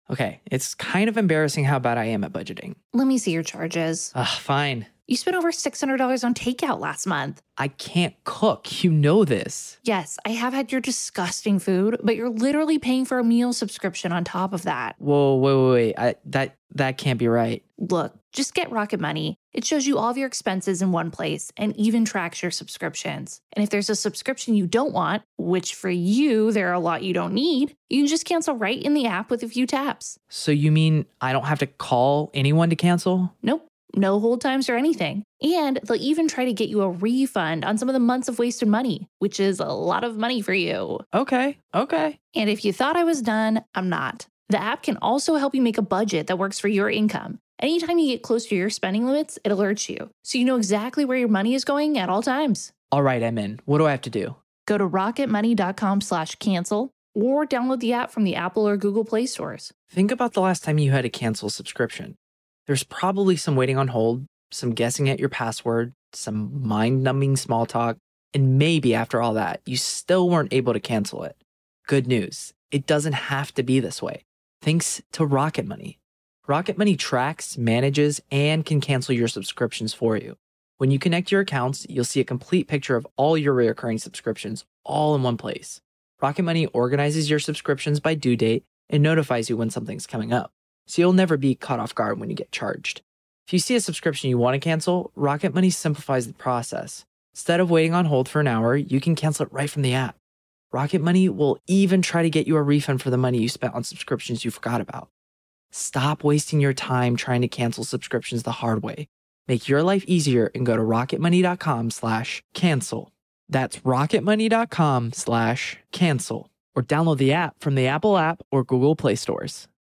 Welcome to "WE EMPOWER" – a radio show inspiring women to unleash their strengths and thrive in various life aspects. Featuring interviews with impressive female personalities across professions and discussions on women-led businesses, the show celebrates pioneers, especially female pioneers in history, science, art, and culture. It also explores self-development, mental health, and wellbeing, showcasing the most inspiriting books on these topics.